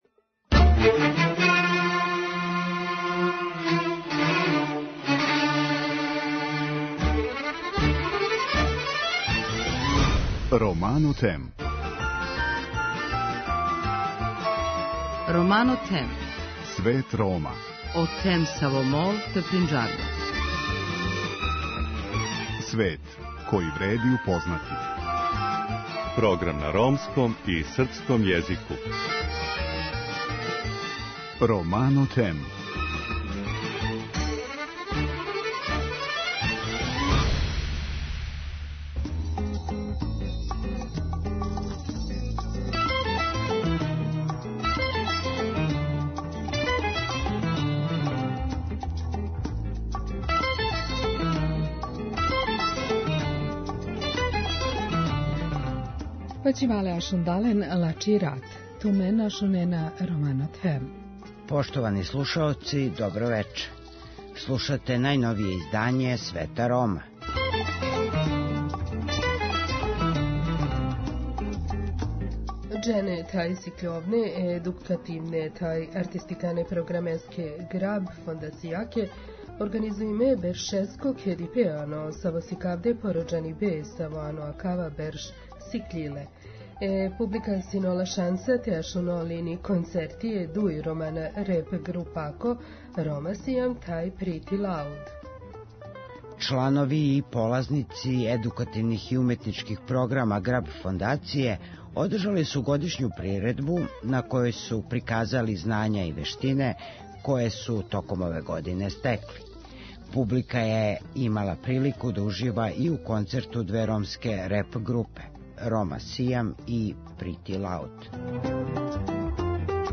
Чланови и полазници образовних и уметничких програма Grubb фондације одржали су годишњу приредбу на којој су приказали знања и вештине које су током ове године стекли. Публика је имала прилику да ужива и у концерту две ромске реп групе, Рома Сијам и Pretty loud.